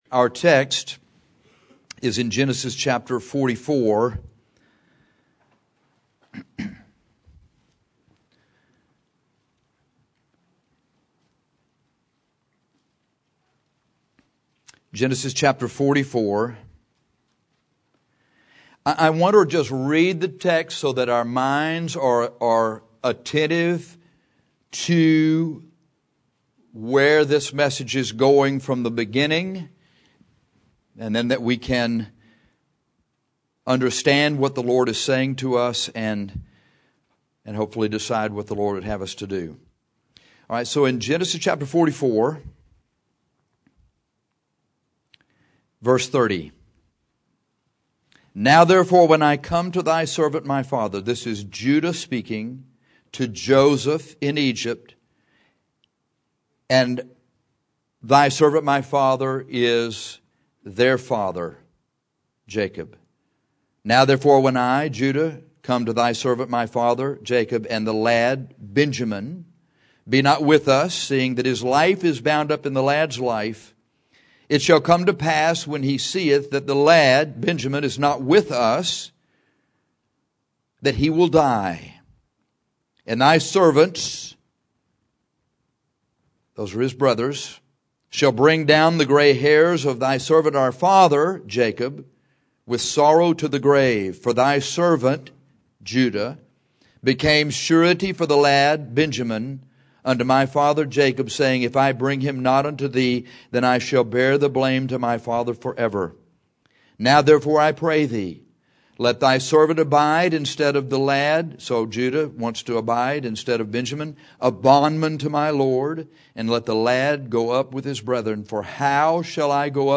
Our text for this sermon is Gen 44: 30–34.